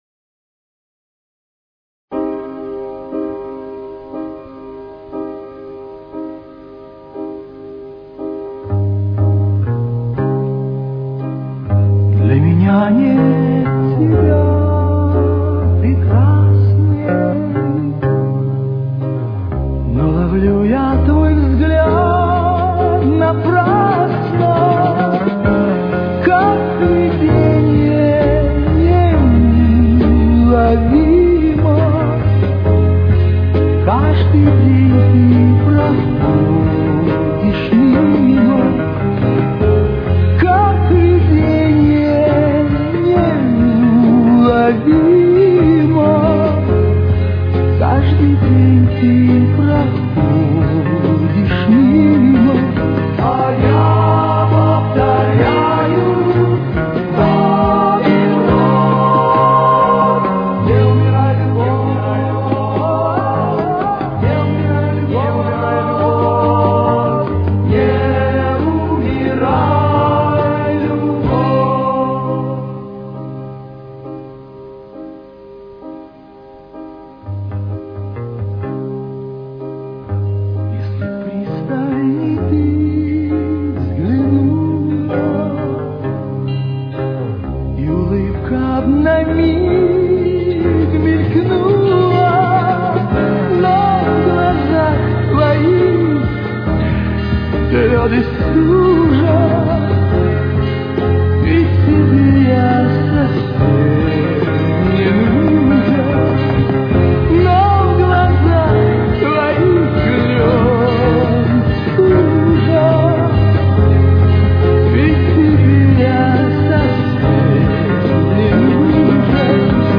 Тональность: До минор. Темп: 66.